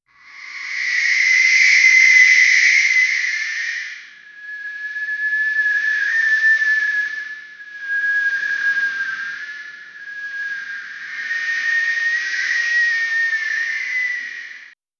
Royalty-free alien-sounds sound effects
sounds-similar-to-the-low-djq6w3zc.wav